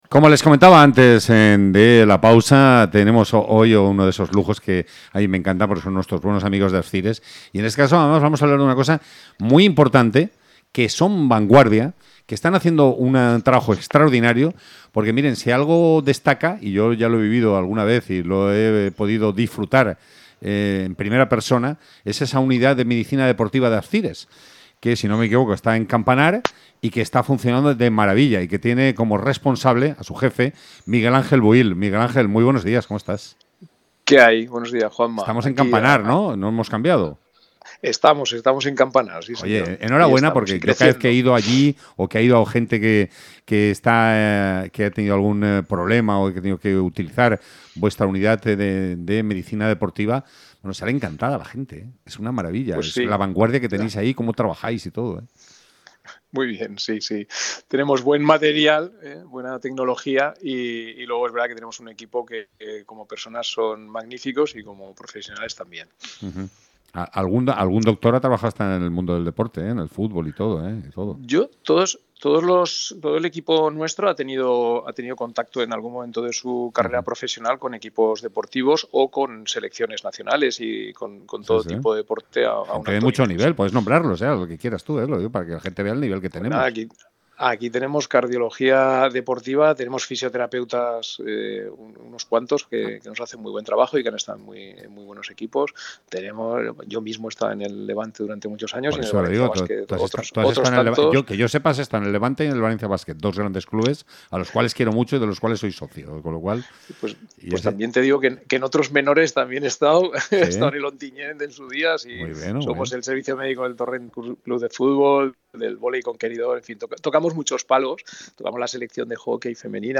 hemos entrevistado